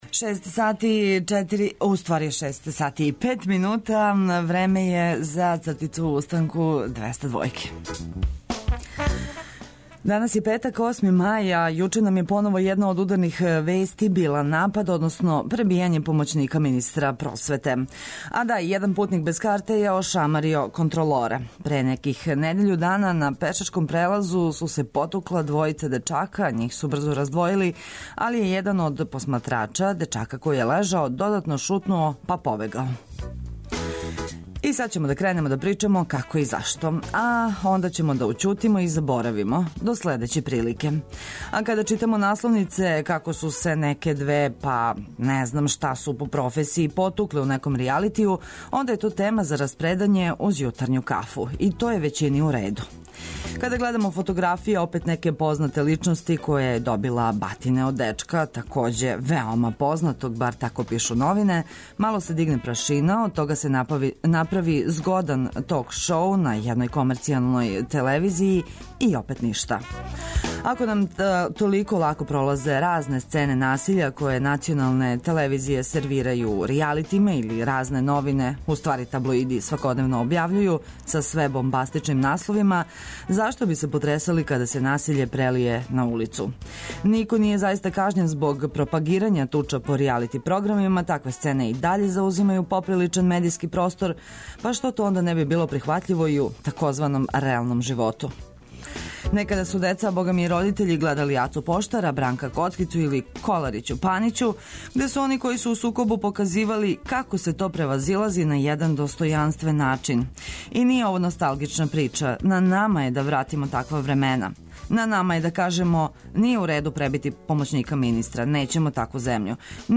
Попијте прву јутарњу кафу уз добар ритам са таласа Београда 202...